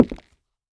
Minecraft Version Minecraft Version latest Latest Release | Latest Snapshot latest / assets / minecraft / sounds / block / nether_wood / step3.ogg Compare With Compare With Latest Release | Latest Snapshot
step3.ogg